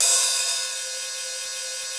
CYM XCHEEZ04.wav